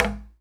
Knock28.wav